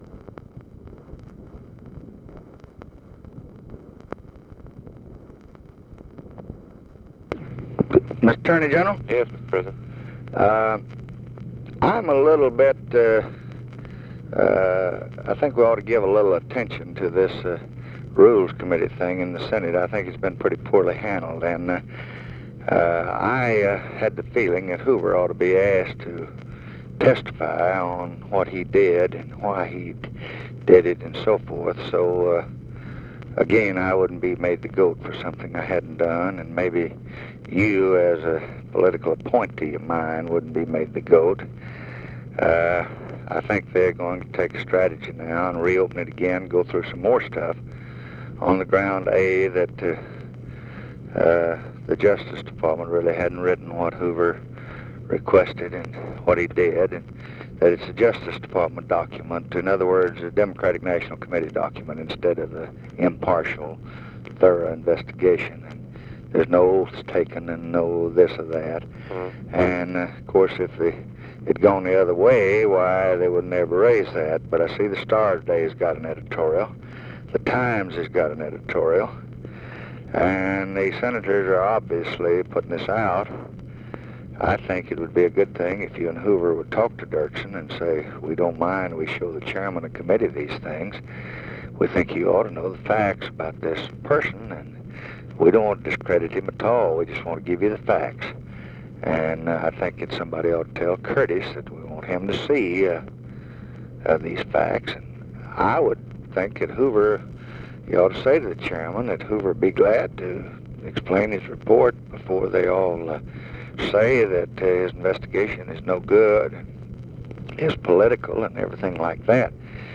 Conversation with NICHOLAS KATZENBACH, March 4, 1965
Secret White House Tapes